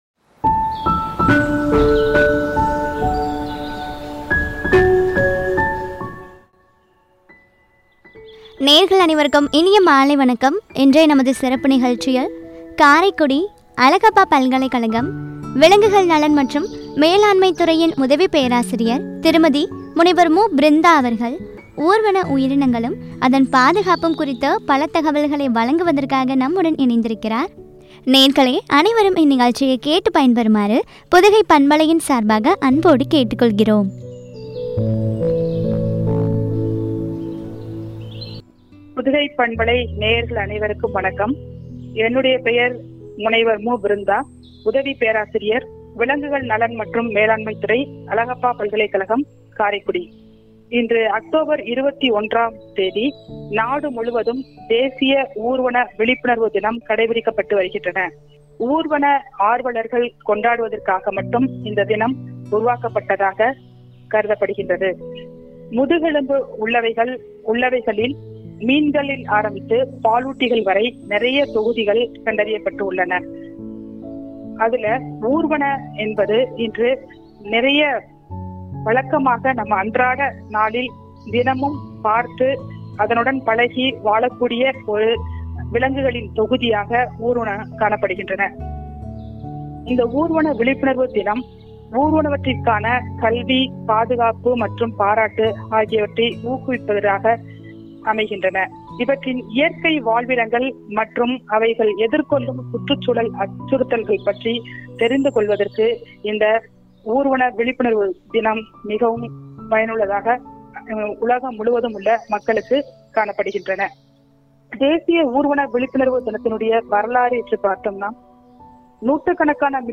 ஊர்வன உயிரினங்களும் பாதுகாப்பும் பற்றிய உரையாடல்.